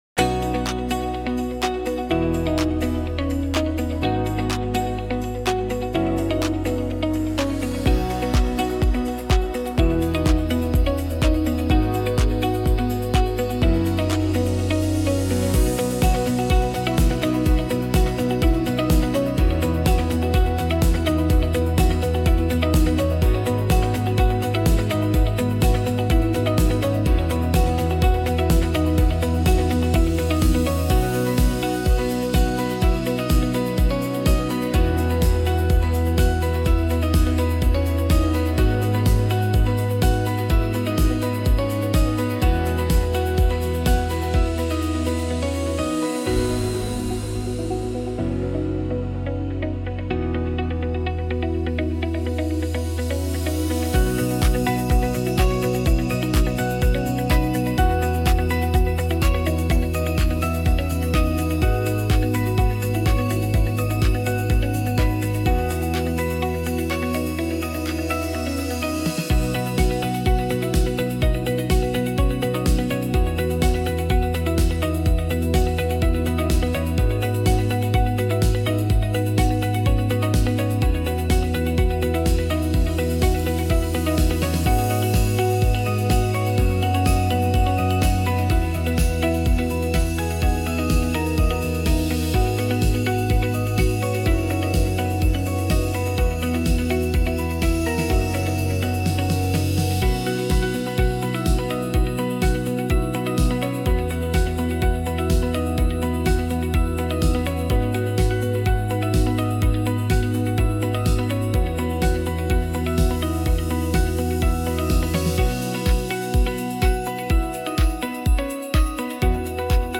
ビジネス・企業向けBGM・ボーカル無し
インストゥルメンタル
ポップス アップテンポ
明るい